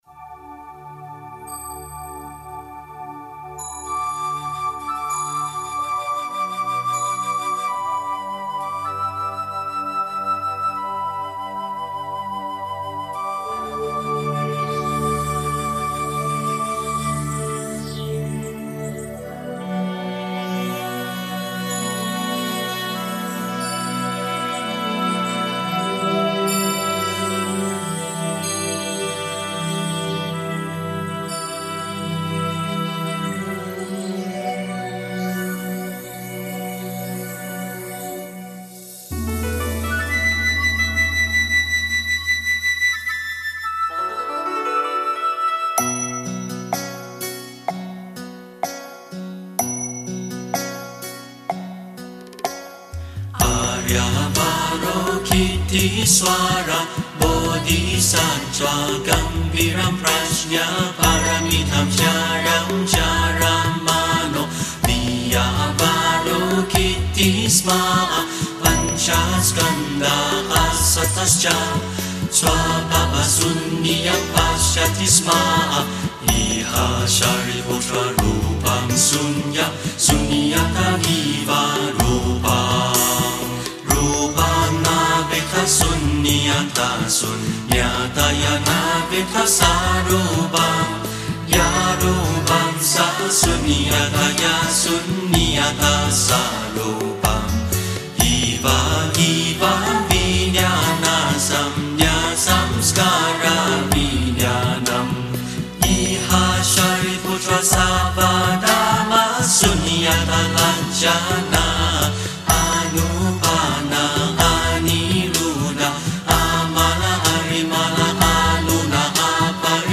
心经音乐播放30分钟 心经歌曲播放 般若波罗蜜多心经音乐 佛教歌曲菠萝菠萝蜜心经音乐 心经音乐播放30分钟睡眠的歌曲是哪个 心经音乐播放 心经音乐梵音 心经音乐播放30分钟视频 心经音乐哪一版比较好 心经音乐印能法师 心经